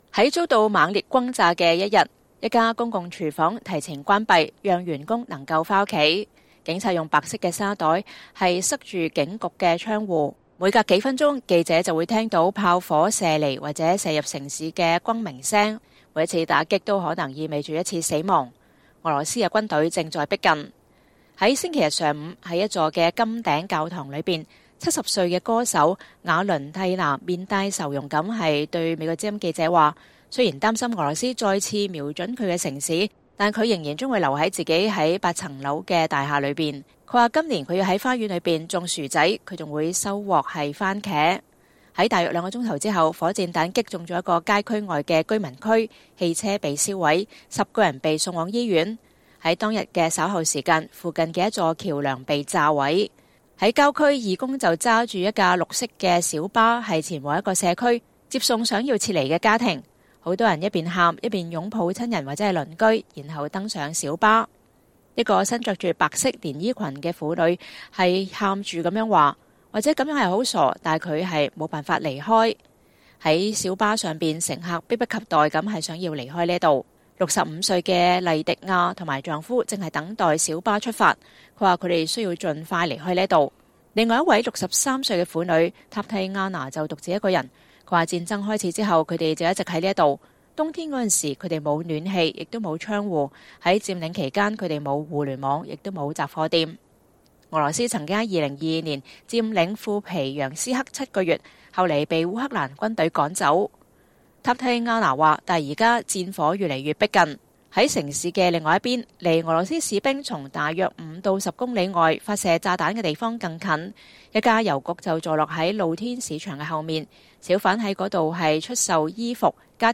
在遭到猛烈轟炸的一天，一家公共廚房提前關閉，讓員工能夠回家。警察用白色沙袋堵住警局的窗戶。每隔幾分鐘，記者就會聽到炮火射離或射入城市的轟鳴聲或砰砰聲。